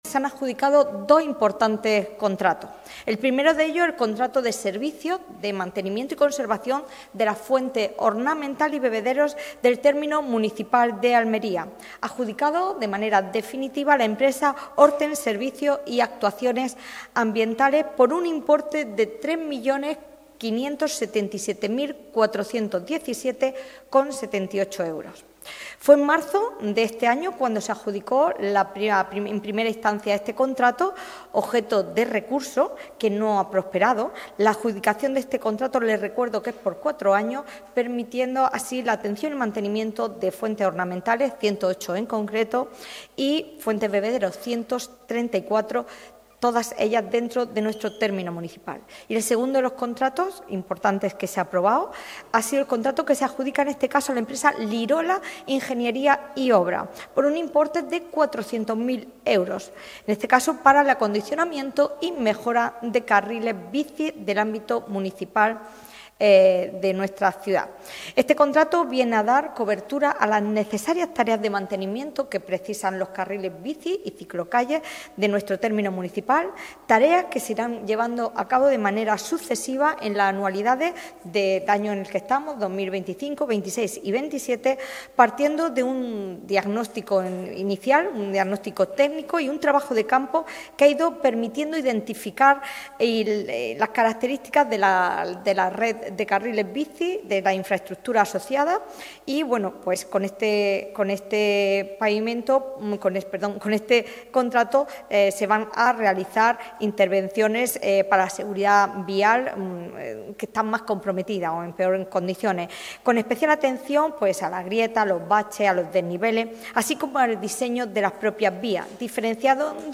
La portavoz del Equipo de Gobierno del Ayuntamiento de Almería, Sacramento Sánchez, ha informado hoy en rueda de prensa sobre los asuntos más relevantes aprobados en la Junta de Gobierno Local celebrada el pasado viernes, reunión de este órgano de gobierno en el que se dio luz verde a más de 40 puntos relacionados con la gestión municipal.